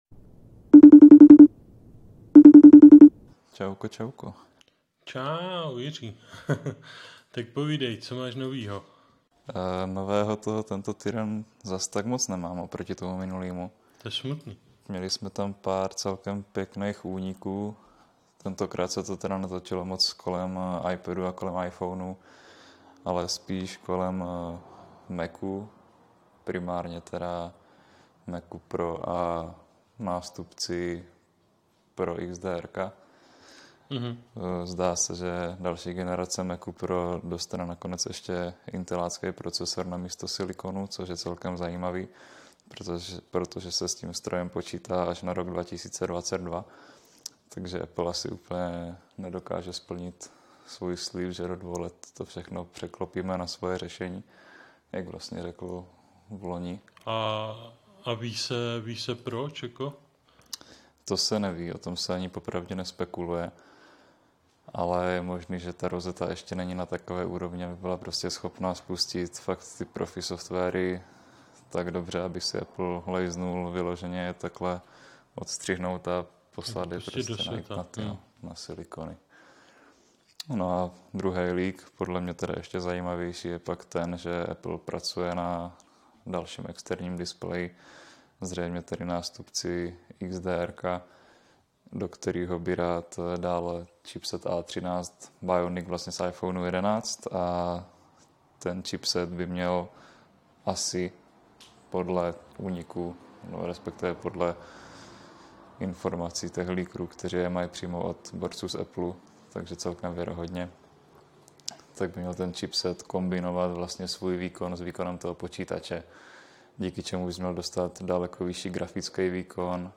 Páteční pokec přes FaceTime s LsA vol. 11: Budoucí Macy, AirPods 3 a nedostatky novinek